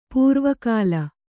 ♪ pūrva kāla